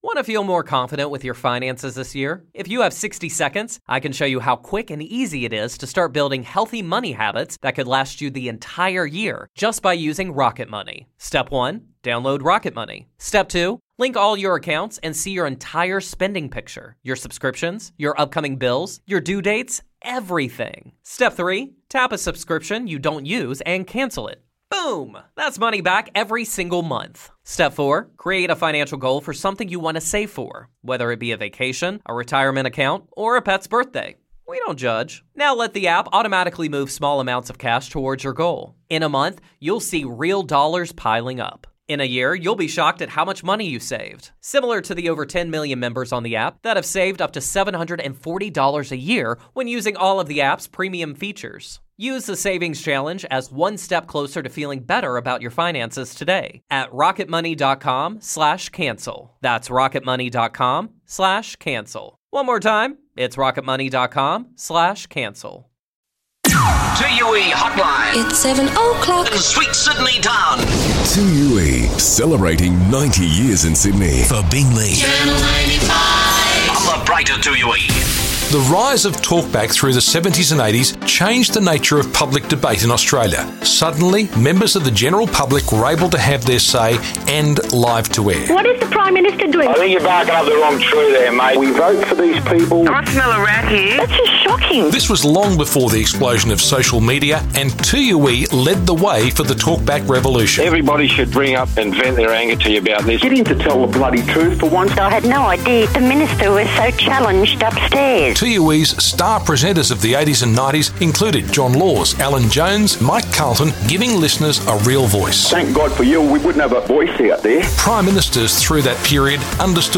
Take a listen back to some classic 2UE flashbacks on 90 years of broadcasting to Sydney and around the globe.